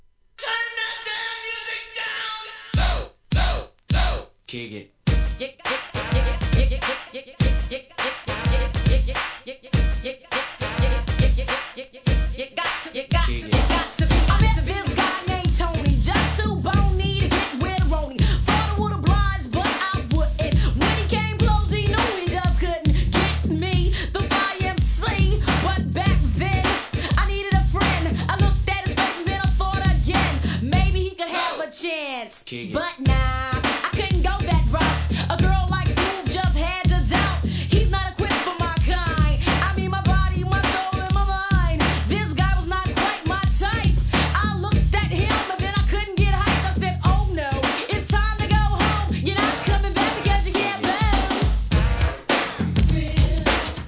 HIP HOP/R&B
跳ねBEATでのフィメールRAP物!!